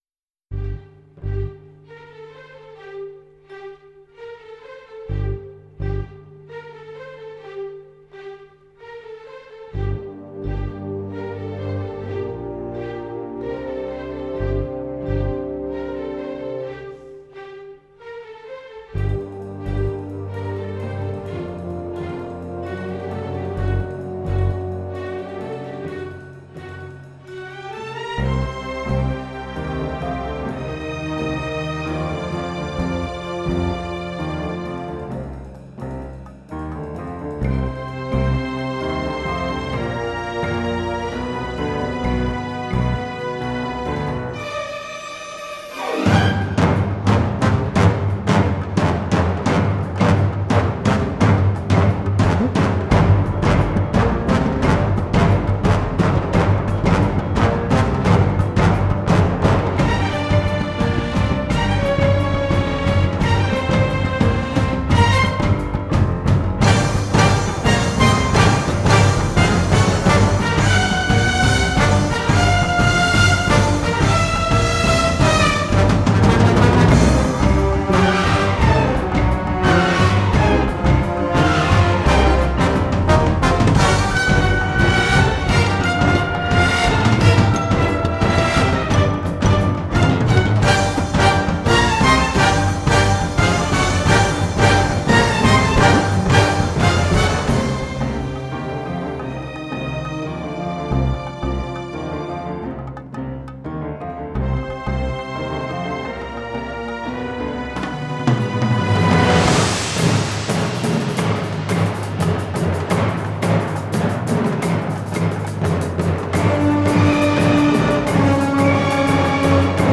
Музыкальная композиция из саундтрека фильма